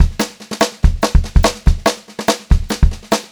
144SPBEAT3-R.wav